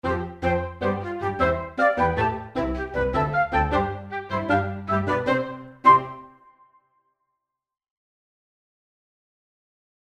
ジングル